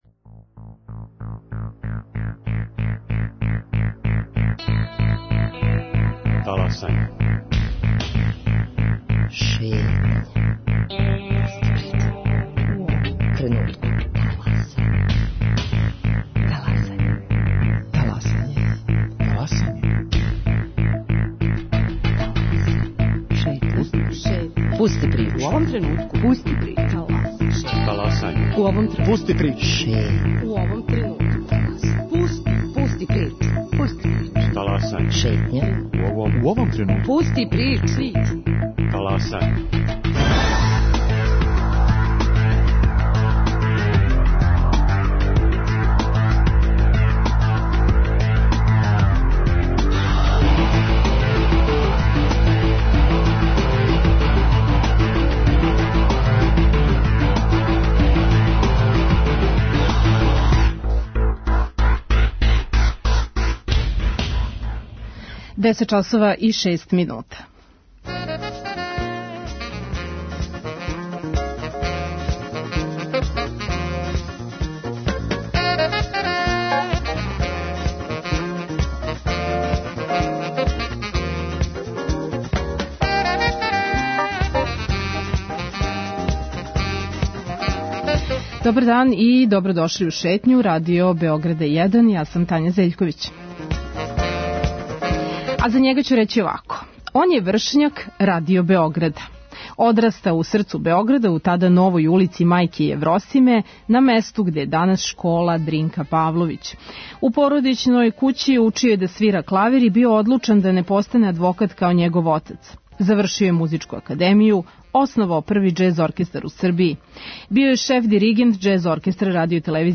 Поводом 93. рођендана Радио Београда, додељена му је награда за животно дело, Златни микрофон. Гост данашње ШЕТЊЕ је композитор и диригент Војислав Бубиша Симић.